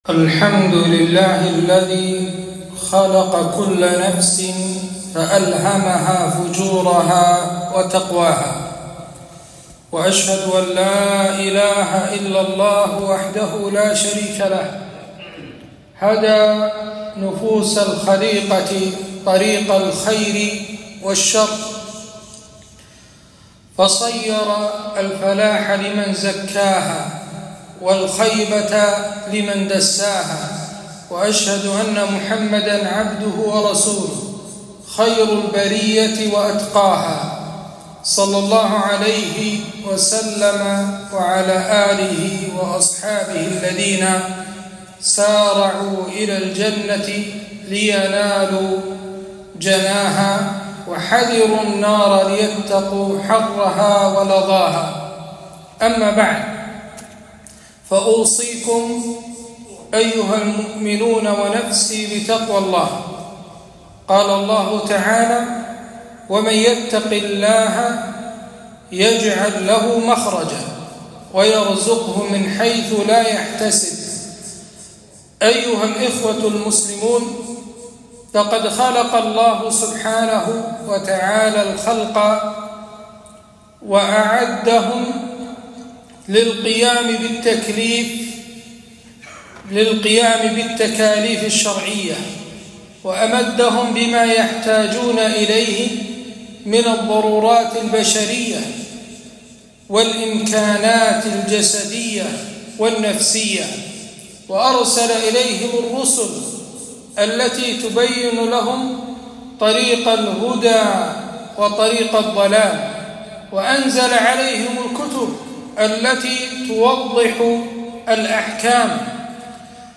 خطبة - ( وهديناه النجدين)